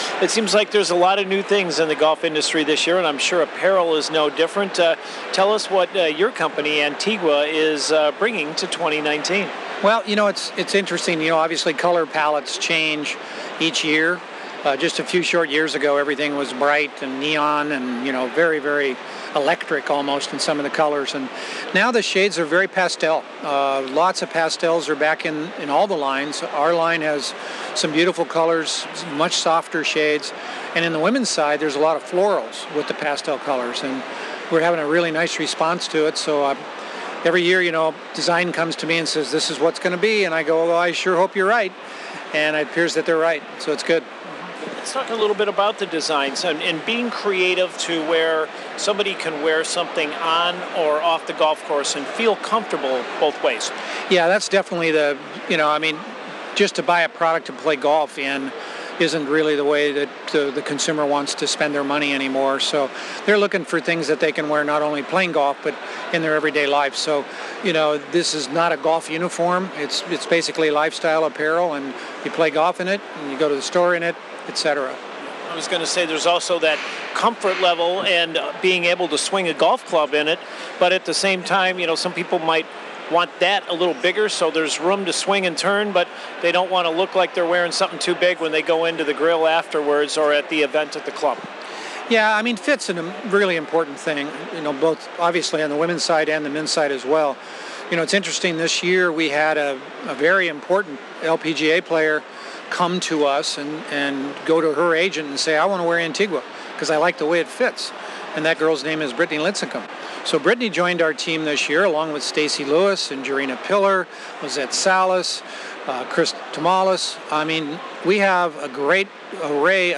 ONE ASPECT of the PGA Merchandise Show is the apparel section, where one can see what the styles will be for the year ahead.